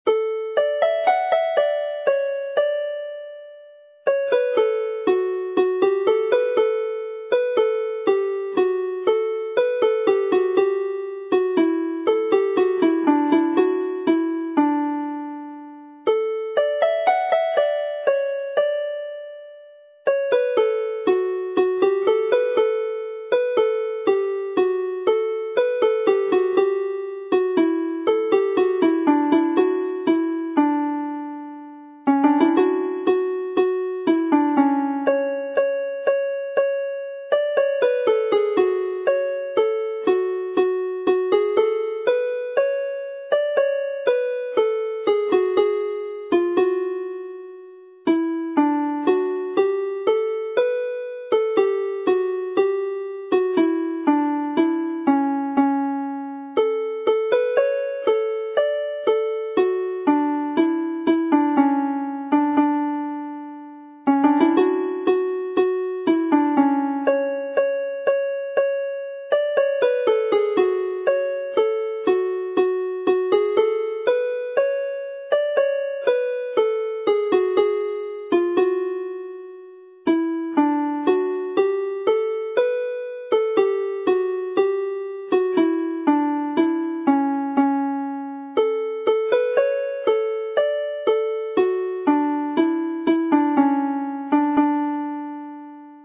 Alawon Cymreig - Set Sawdl y Fuwch - Welsh folk tunes to play -
The daisy (major version of Sawdl y Fuwch)